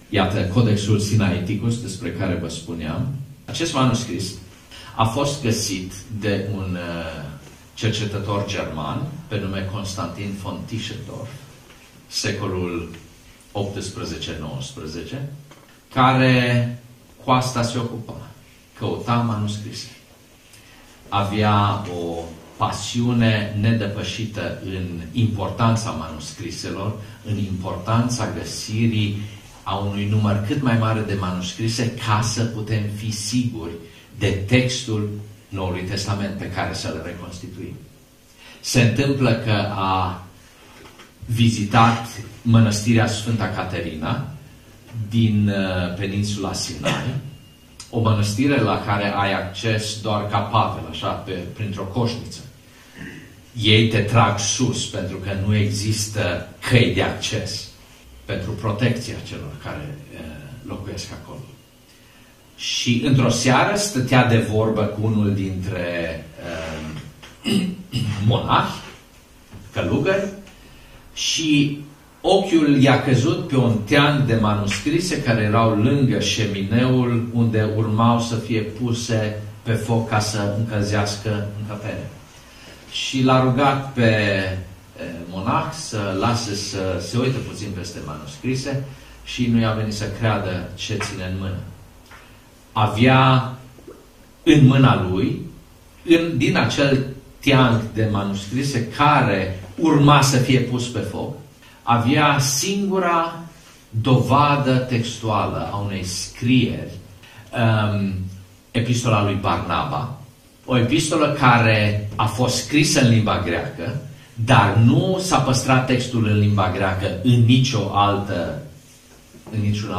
La „Serile Dialogos” în Arad